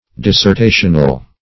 Search Result for " dissertational" : The Collaborative International Dictionary of English v.0.48: Dissertational \Dis`ser*ta"tion*al\, a. Relating to dissertations; resembling a dissertation.